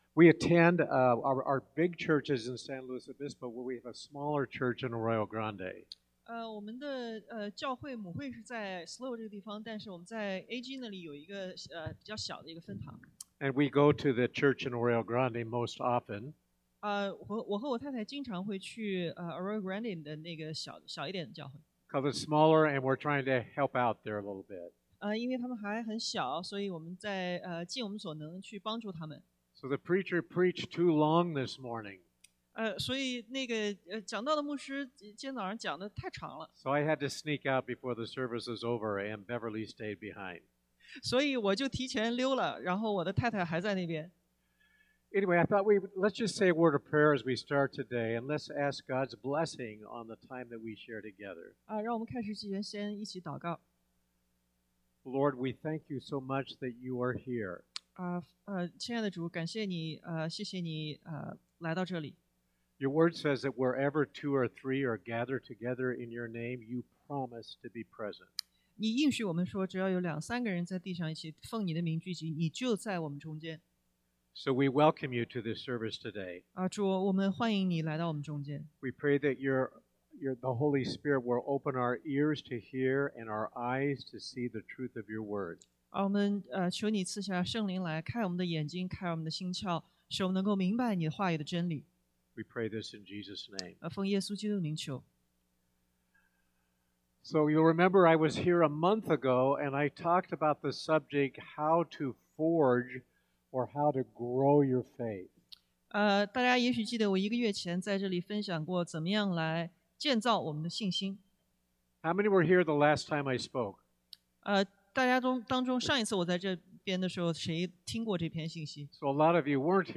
提前 1:18-26 Service Type: Sunday AM Wage the Good warfare 打那美好的仗（1 Tim.